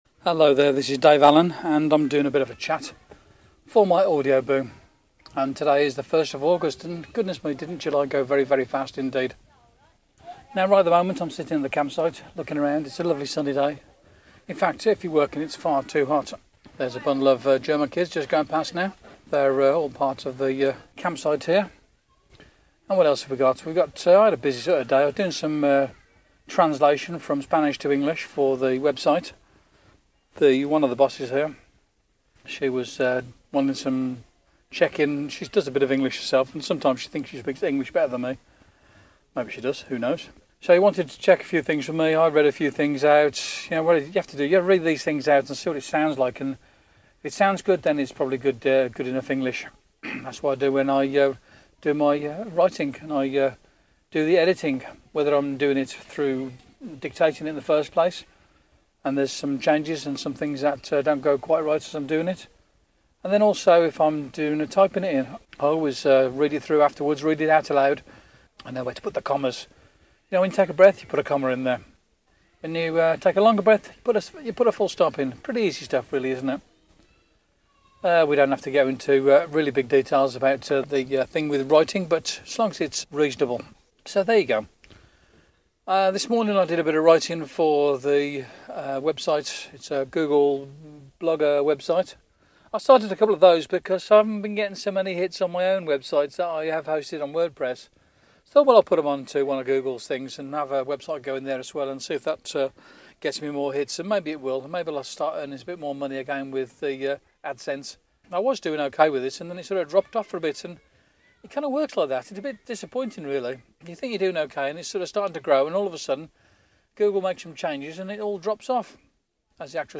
At the Campsite chatting about writing and travelling